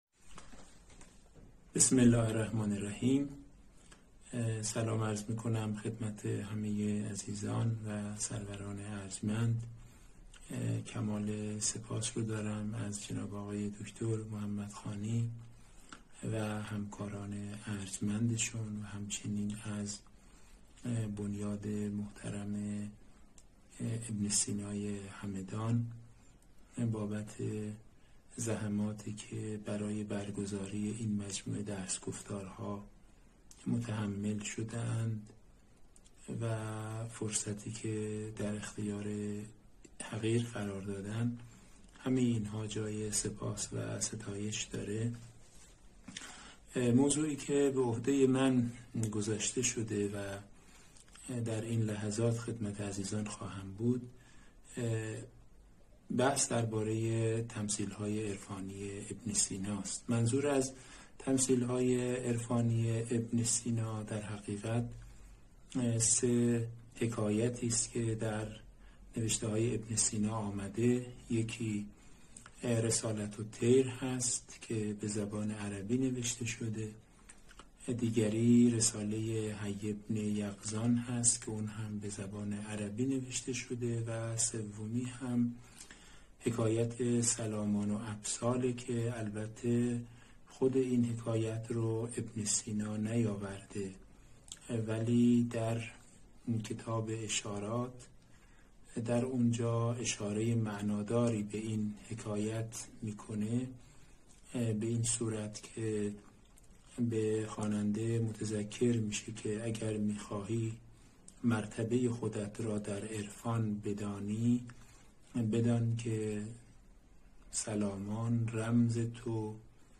این درس‌گفتار به صورت مجازی از اینستاگرام شهر کتاب پخش شد. موضوع حکمت مشرقی ابن‌سینا یکی از وجوه بحث‌انگیز تاریخ فلسفه در جهان اسلام است.